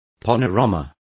Also find out how panorama is pronounced correctly.